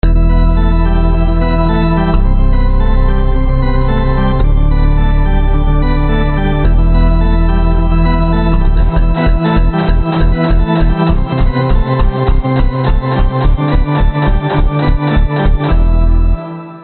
小号（wah distorted）。 还有我的钢琴和风琴，音轨以压缩文件形式上传。
Tag: 爵士乐